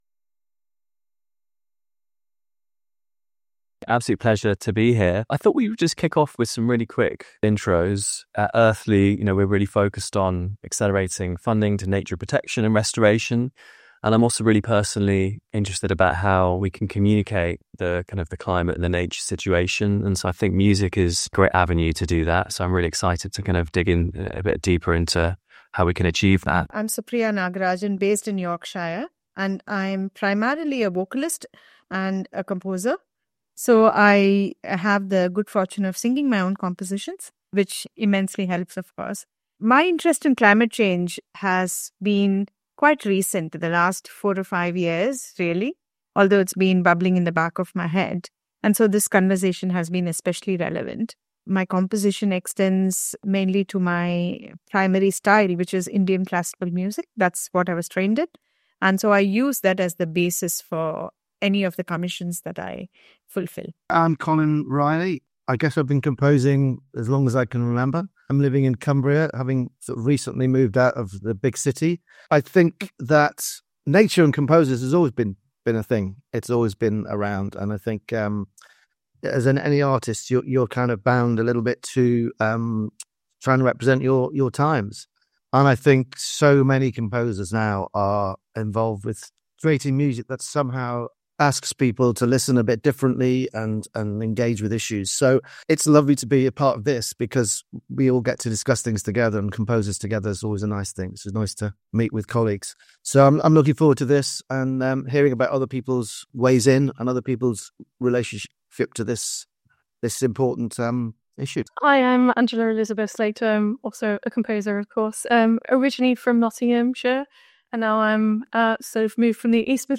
talk on music and climate change